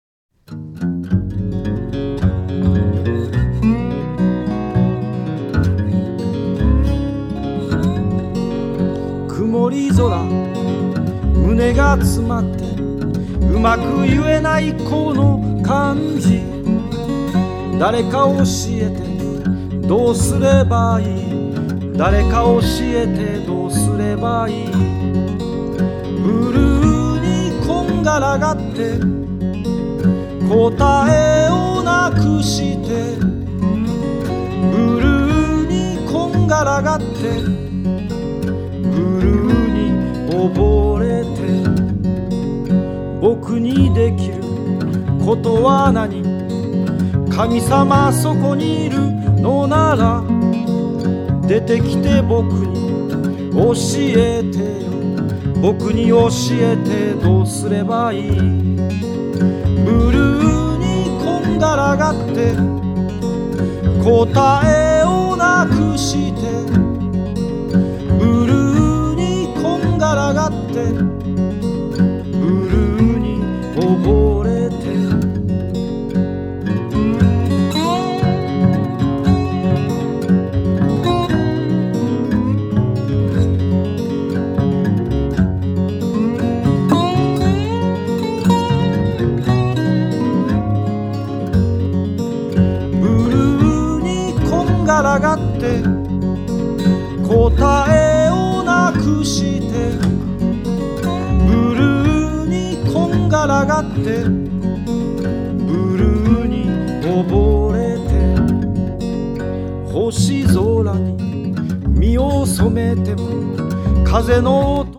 独特の声質と言葉のリズムが際立つ素晴らしい内容となっていますね！